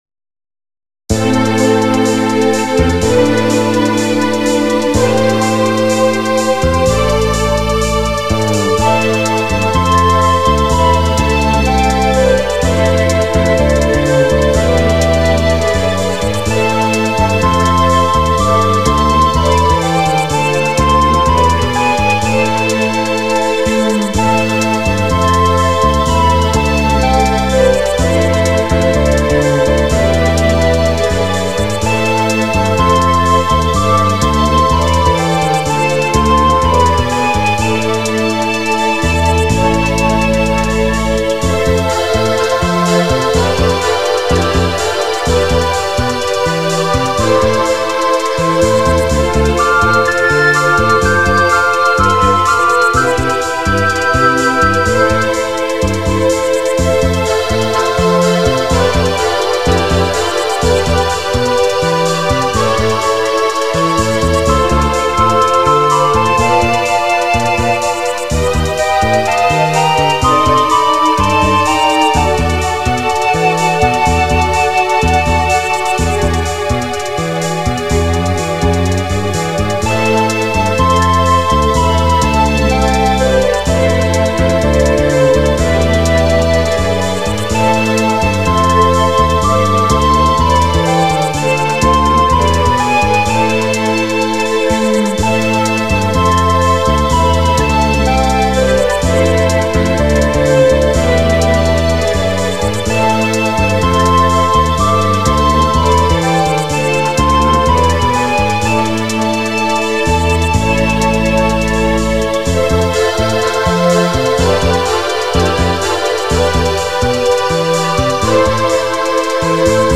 オリジナルに近づけて耳コピ。